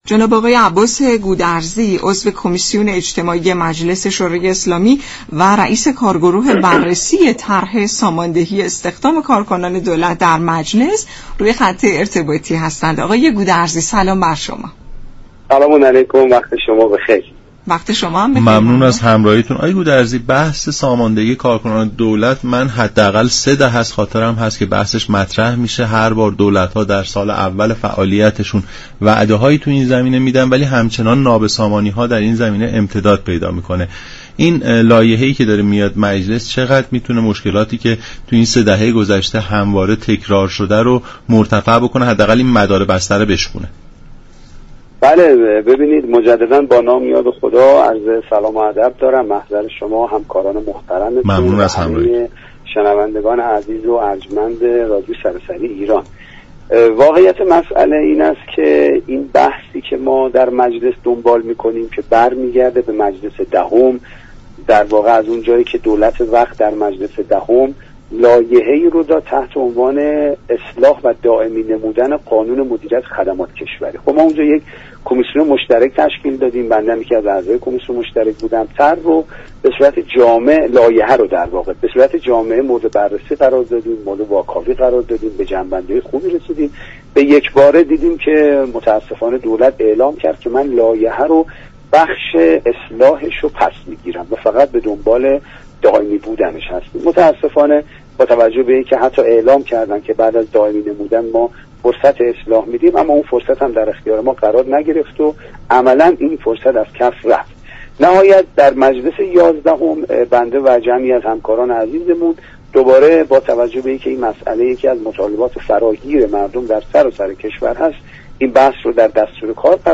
به گزارش شبكه رادیویی ایران، عباس گودرزی عضو كمیسیون اجتماعی مجلس شورای اسلامی و رییس كارگروه بررسی طرح ساماندهی استخدام كاركنان دولت در مجلس در برنامه «نمودار» به جزئیات خبر ساماندهی كاركنان دولت پرداخت و در پاسخ این پرسش كه طرح اخیر مجلس چقدر می تواند در از میان بردن مشكلات تاثیرگذار باشد؟